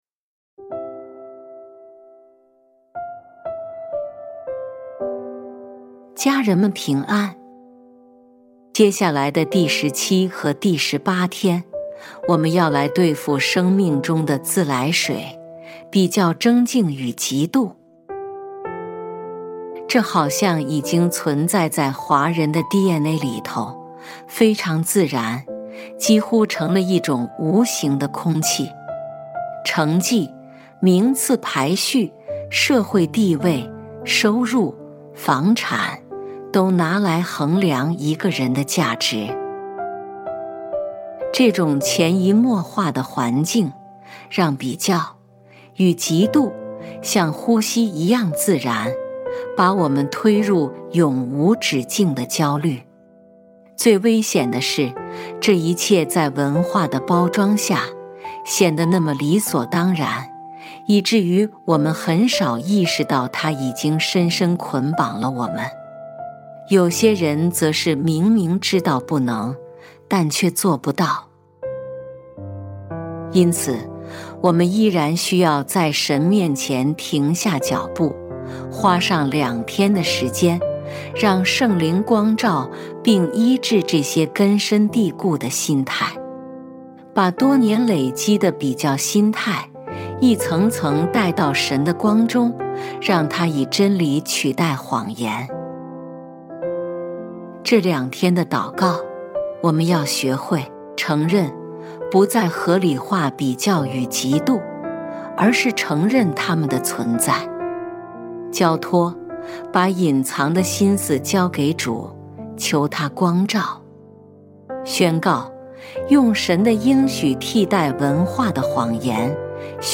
本篇是由微牧之歌撰稿祷告及朗读 第17-18天 神忘了我，把我排到了最后吗？ 第一音源 第二音源 &nbs…